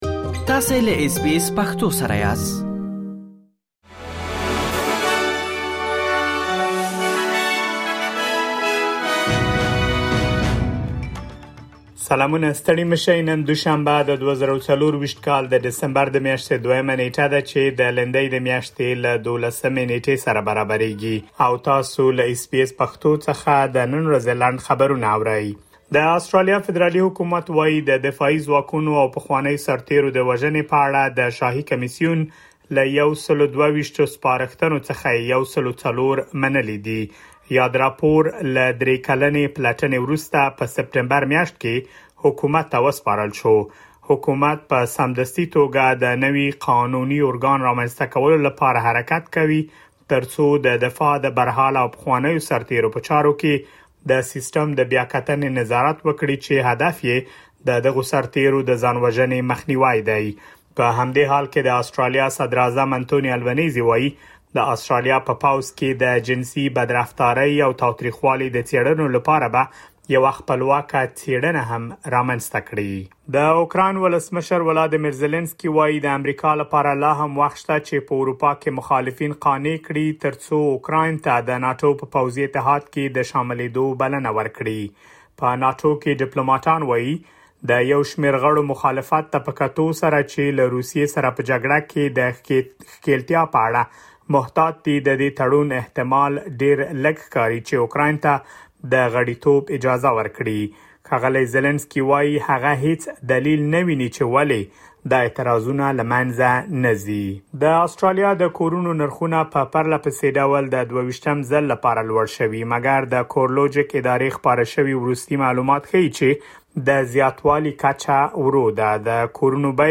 د اس بي اس پښتو د نن ورځې لنډ خبرونه |۲ ډسمبر ۲۰۲۴
د اس بي اس پښتو د نن ورځې لنډ خبرونه دلته واورئ.